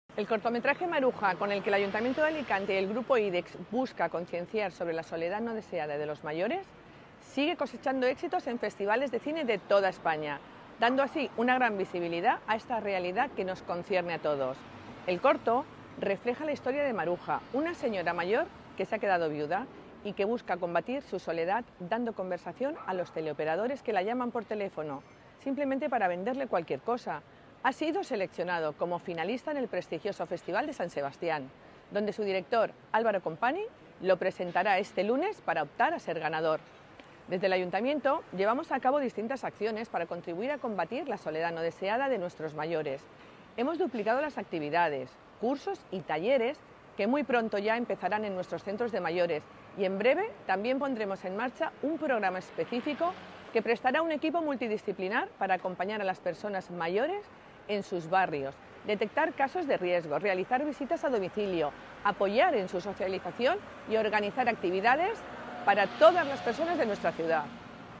Corte de voz de Begoña León, concejal de Mayores.